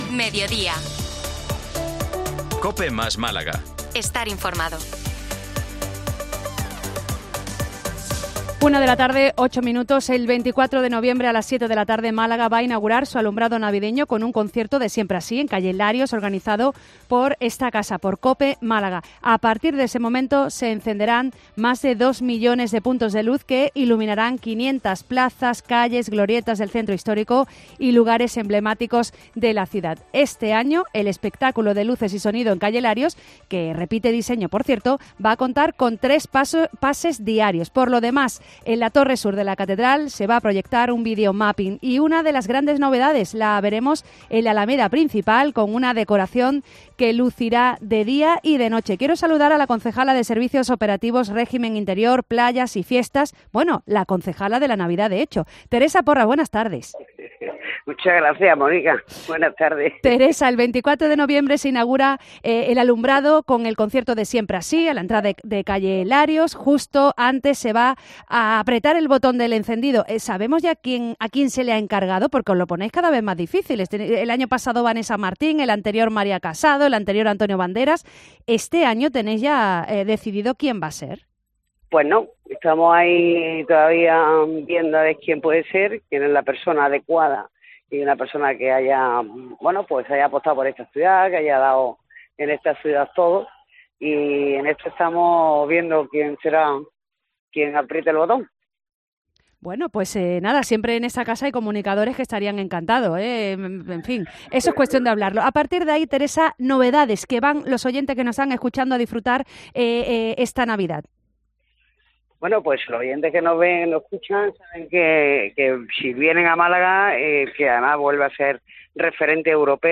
Este viernes, 24 de noviembre, a las 19.00 horas, Málaga ha inaugurado su alumbrado navideño con un concierto de Siempre Así en la calle Larios organizado por COPE Málaga.